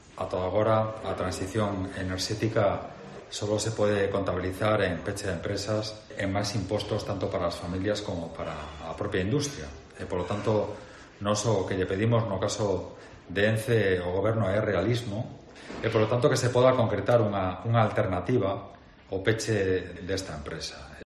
Declaraciones del conselleiro de Industria sobre el futuro de ENCE en Pontevedra